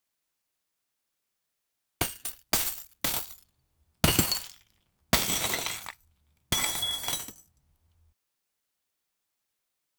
Bites
bites-zk4gayds.wav